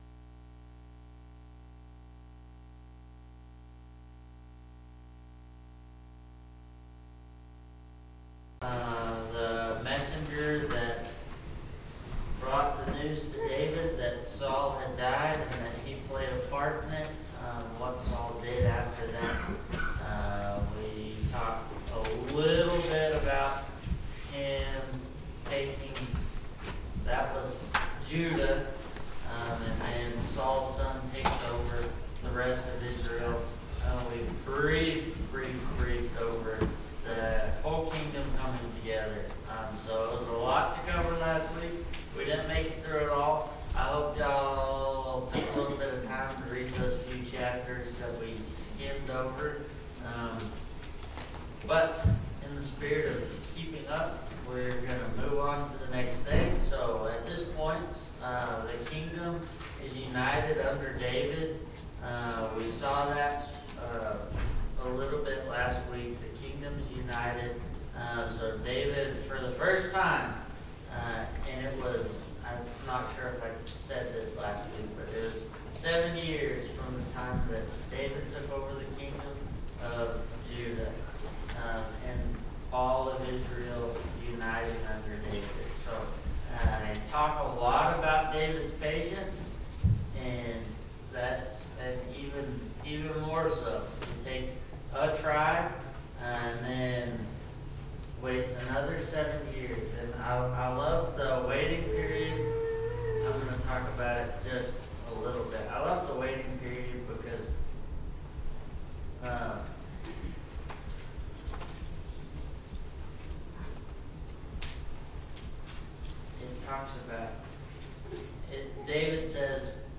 Sunday AM Bible Class